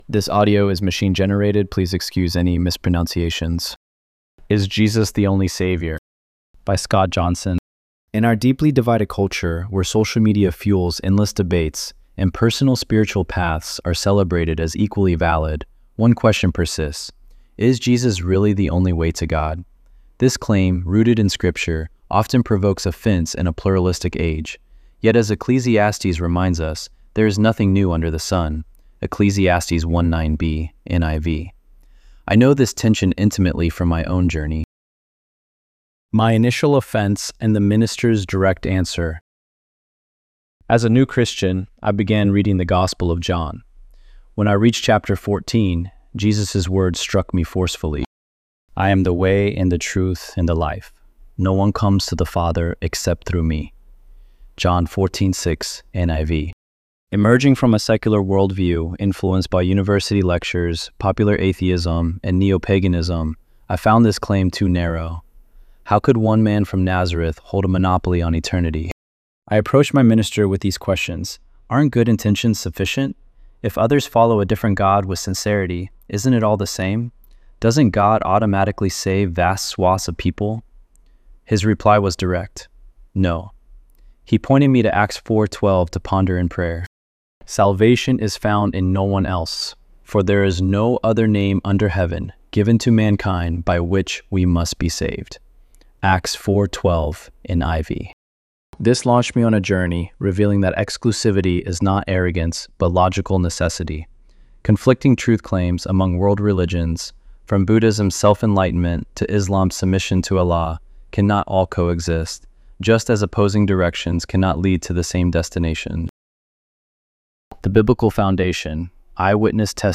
ElevenLabs_3_18.mp3